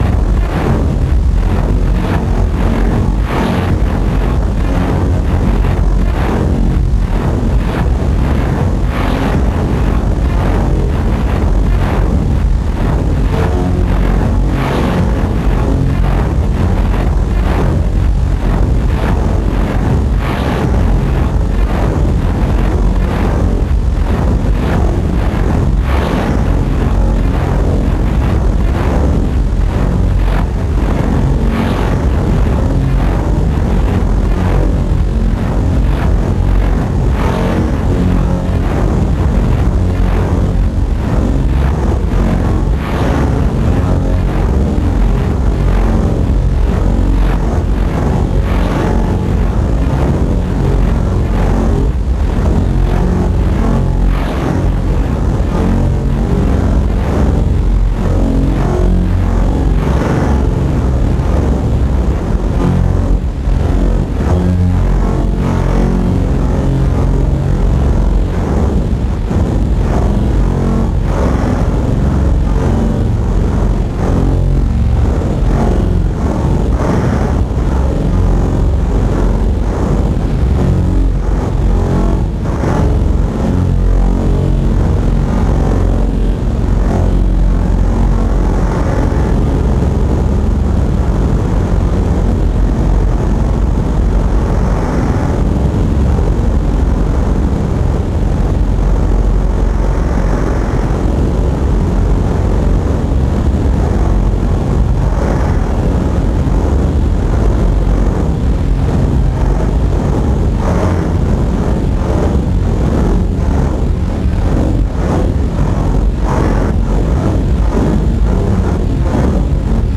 Worked out how to sample again :sweat_smile: Here’s a sampled drum loop through some P-6 master FX and Mojave into a Tascam and then resampled back into the P-6 and sent through the same setup a few times: